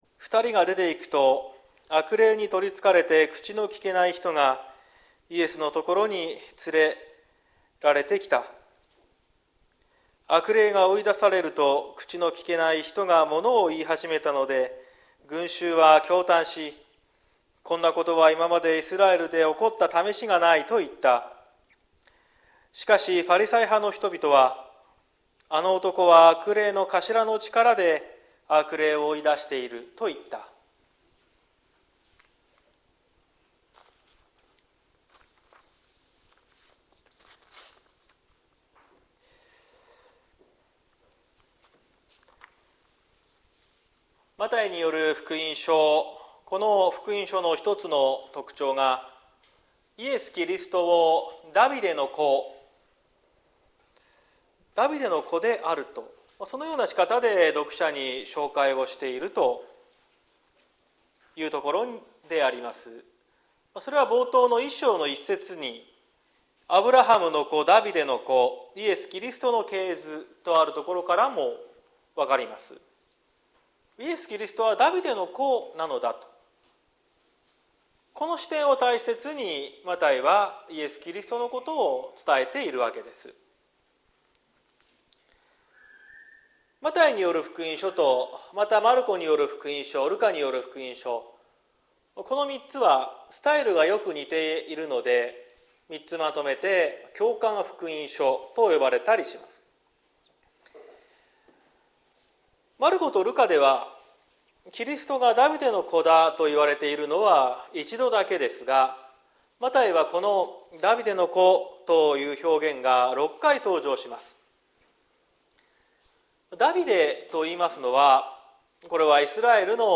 2023年09月10日朝の礼拝「二つの態度」綱島教会
綱島教会。説教アーカイブ。